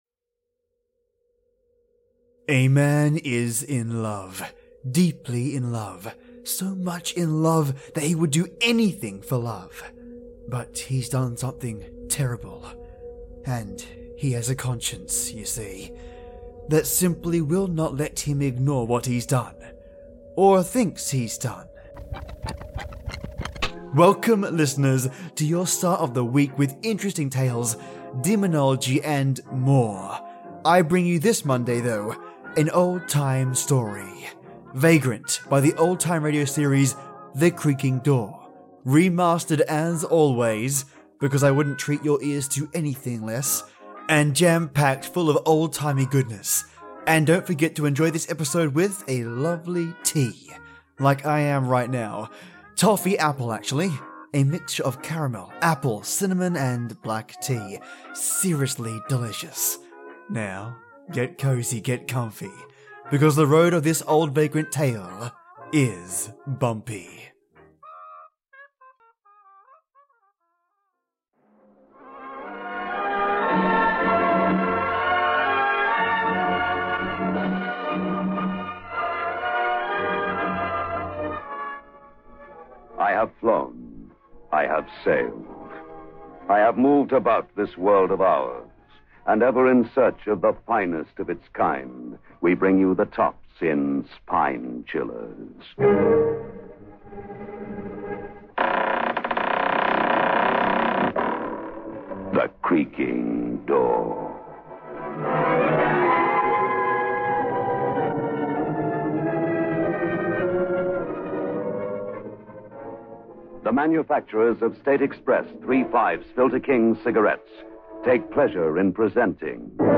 Welcome you lovely listeners I have for you another Creaking Door episode, and seeing as my voice is not 100% up to scratch just yet, I’m downing as much tea, and lemsip as I can, whilst implementing new ways to master this old audio. I’ve been using new applications and new filters to help with gleaming more quality out of existing old records, today I get to show you the results, let’s see what you think.